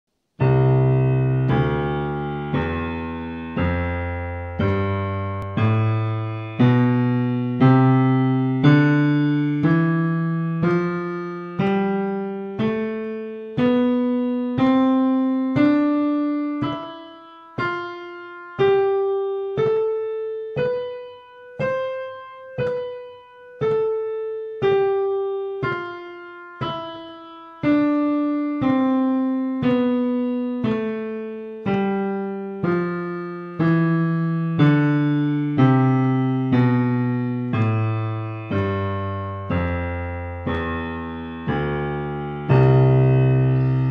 saregama-pitch.wav